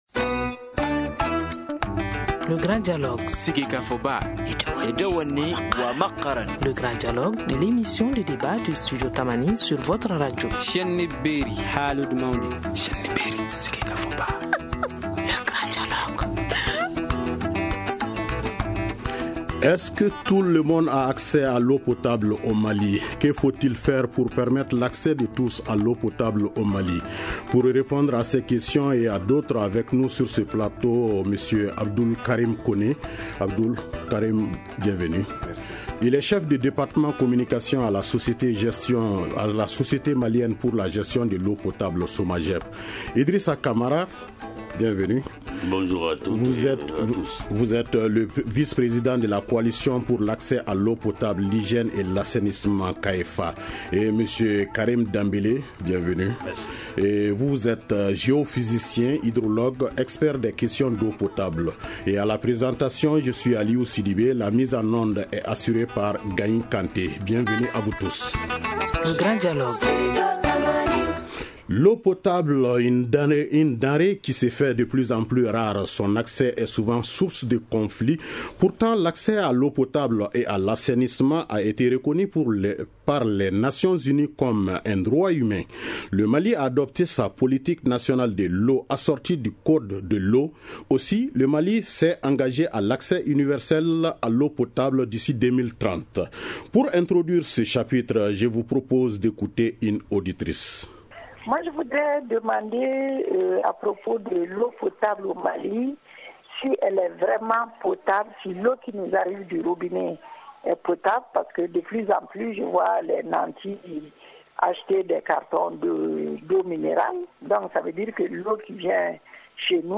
Que faut il faire pour permettre l’accès de tous a l’eau potable au Mali ? Pour répondre a ces questions studio Tamani et certains acteurs de l’accès a l’eau potables se prononceront dans ce grand dialogue.